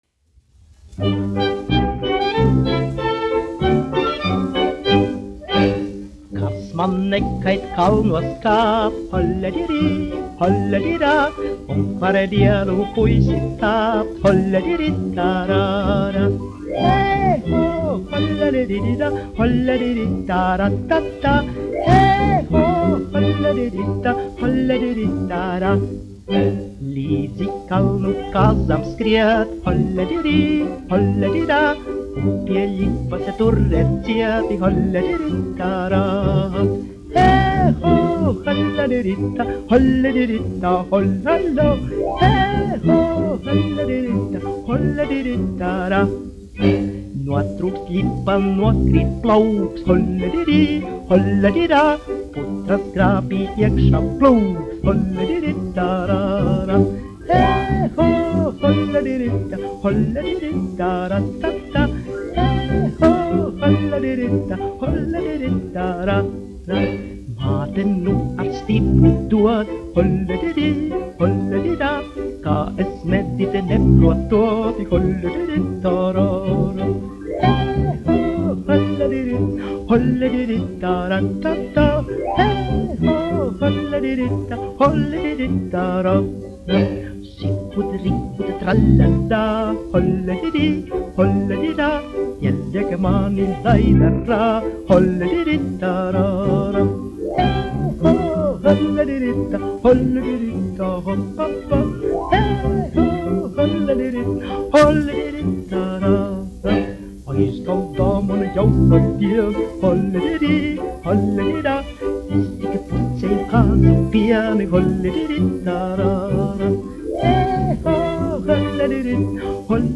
1 skpl. : analogs, 78 apgr/min, mono ; 25 cm
Dziesmas (augsta balss) ar instrumentālu ansambli
Humoristiskās dziesmas